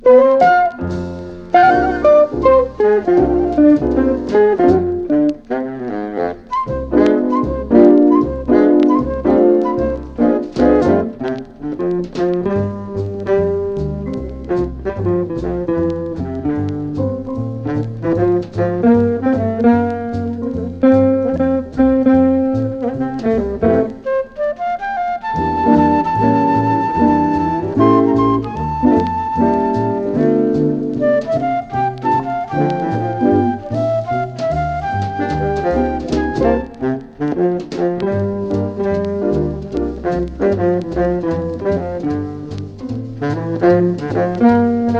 粒立ちの良い音が軽やかかつ流麗に、ときにスウィンギンに。
Jazz　USA　12inchレコード　33rpm　Mono